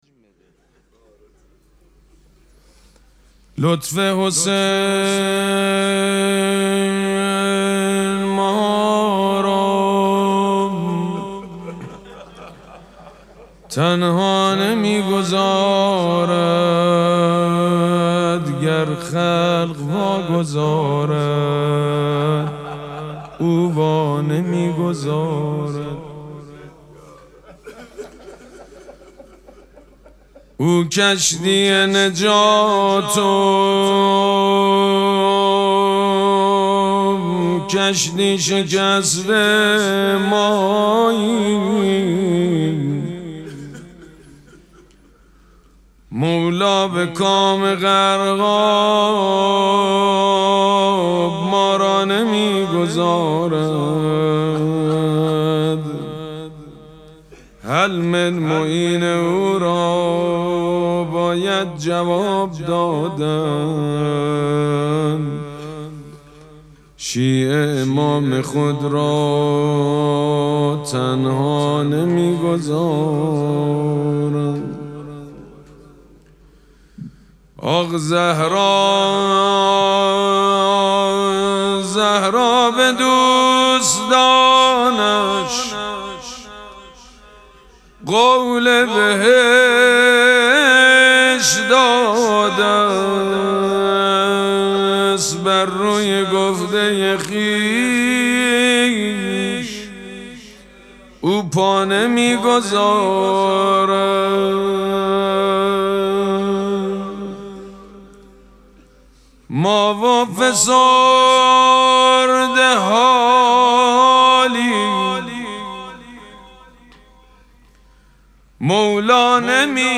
مراسم مناجات شب چهاردهم ماه مبارک رمضان
حسینیه ریحانه الحسین سلام الله علیها
شعر خوانی
حاج سید مجید بنی فاطمه